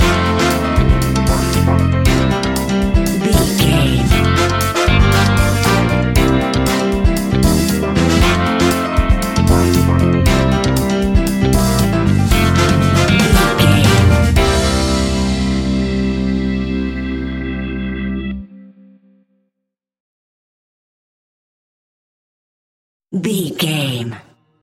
Ionian/Major
flamenco
groove
maracas
percussion spanish guitar